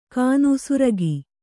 ♪ kānu suragi